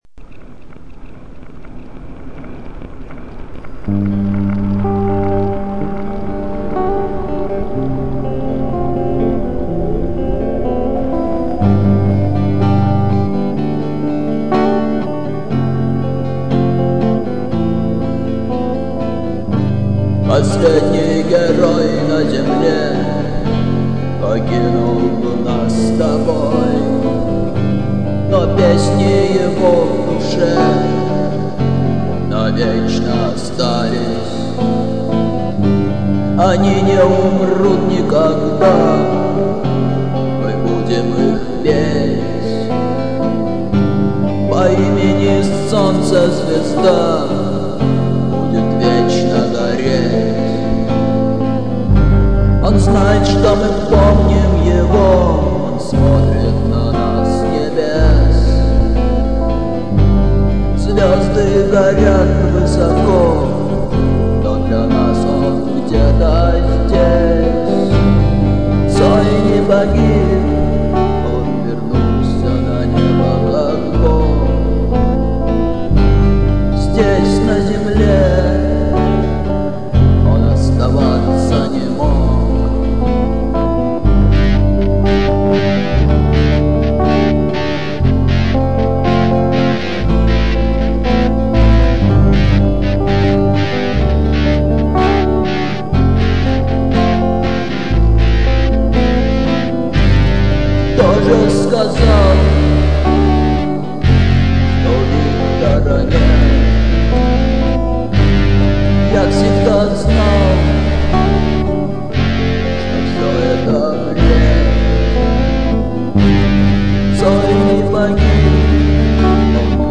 а к чему там электро гитара? 17.07.2009 10:32